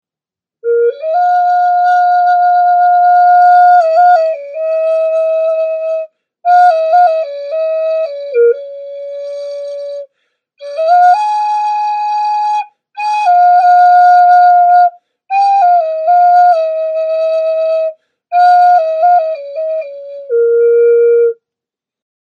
Black Jaguar Aztec Flute
Hand carved black Jaguar Ceramic Flute is hand tuned to produce melancholic sound.
You can check out the sound of this particular flute in the top description.
black-jaguar.mp3